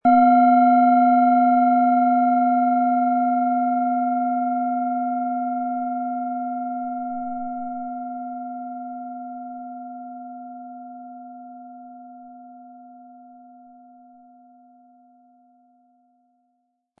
Planetenton 1
Von Meisterhand getrieben und somit von Hand gearbeitete Klangschale aus einem Traditionsbetrieb.
Der passende Klöppel ist kostenlos dabei, der Schlegel lässt die Klangschale harmonisch und wohltuend anklingen.
MaterialBronze